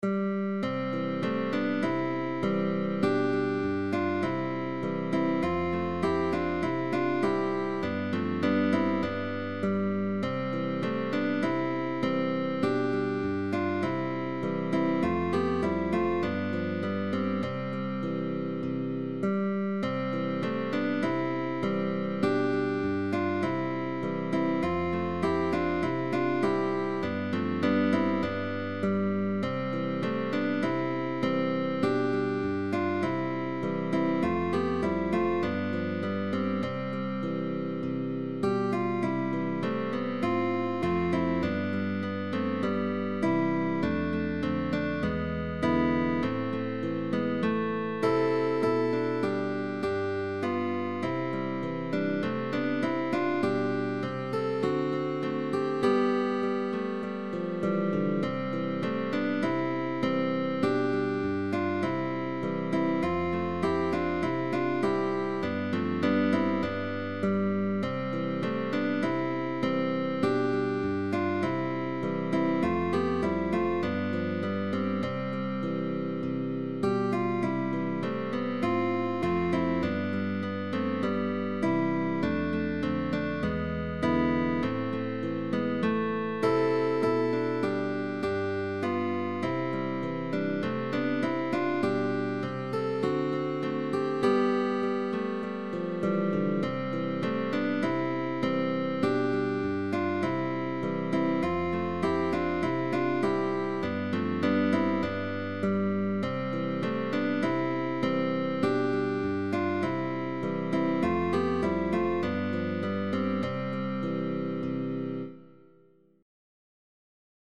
by guitar trio ensemble.
GUITAR TRIO
Tag: Baroque